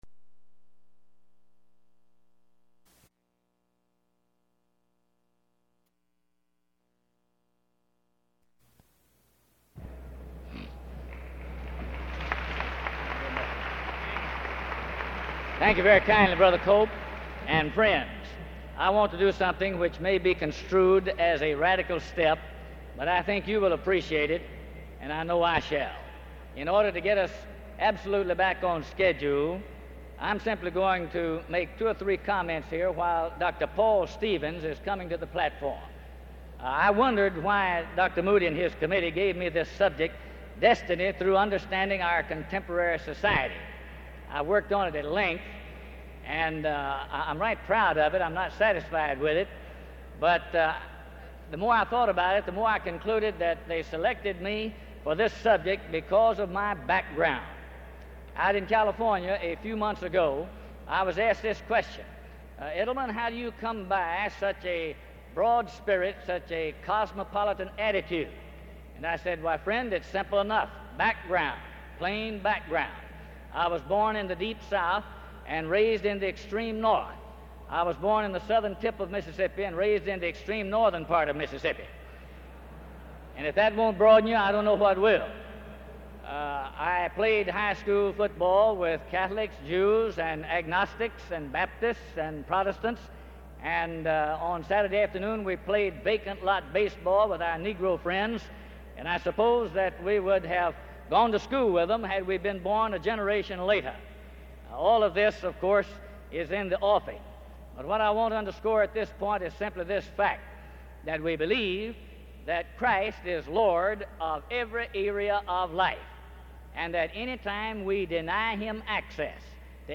The 1965 Pastor’s Conference was held May 31-June 1, 1965, in Dallas, Texas.